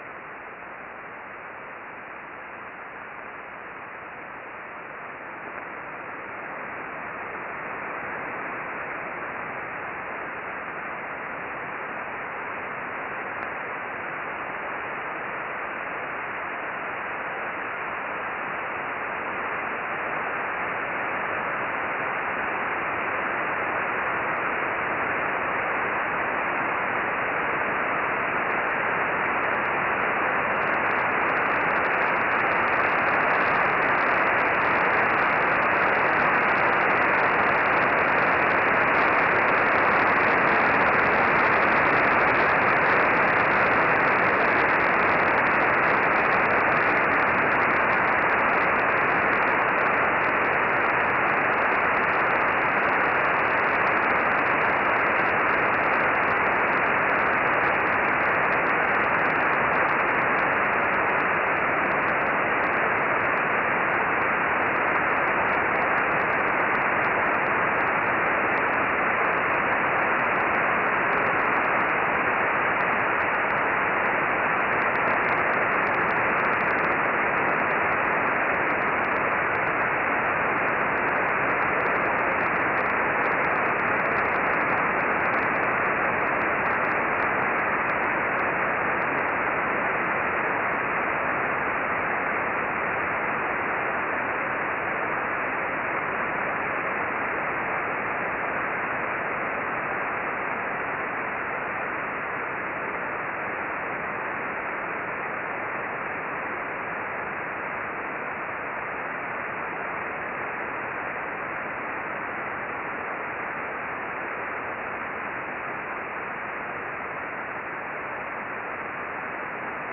Solar Radio Observations for 03 June 2012
The above chart shows a very powerful solar burst with a peak at 19.5 MHz of about 32 million kelvin.